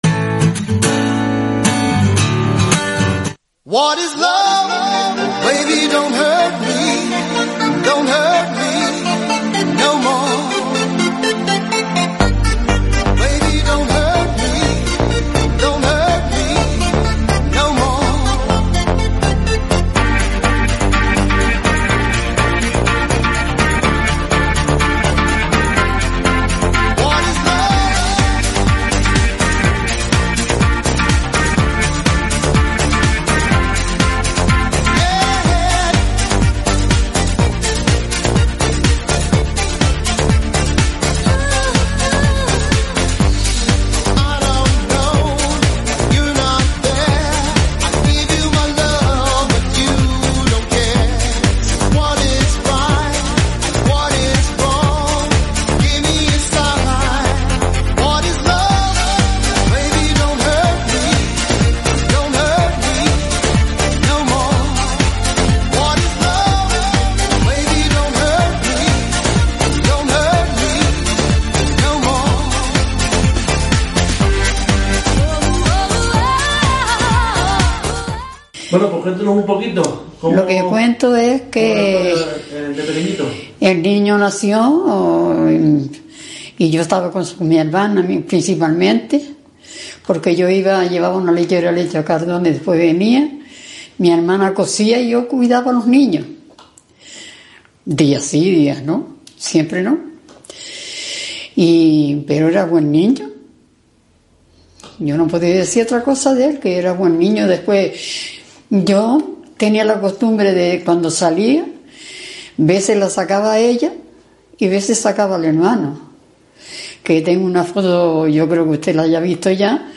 El Sur Despierta Entrevista